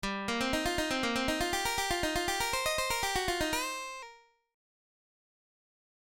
Blues lick > lick 9